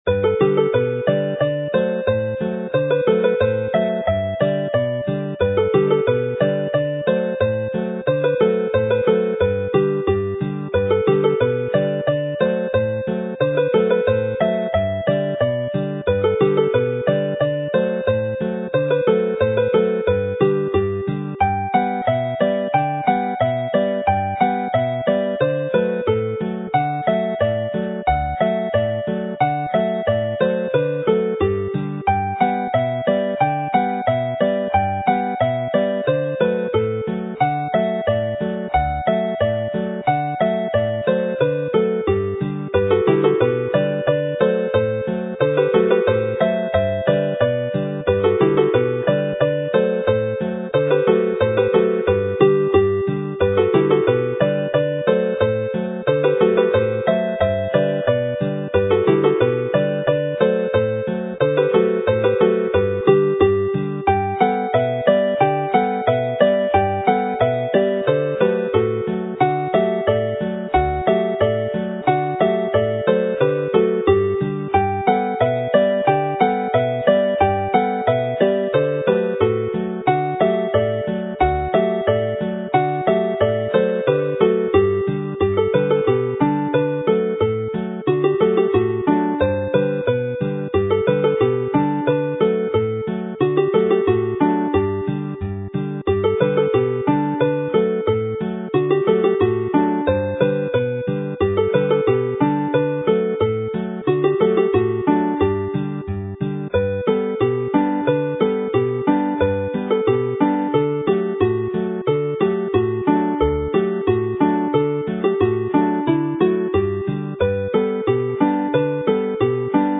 A simple harmony follows as the second tune and 'Waun Oer' (The Cold Meadow), written by the well-know accordionist and triple harpist Rhiain Bebb, is a variation with harmony and counterpoint.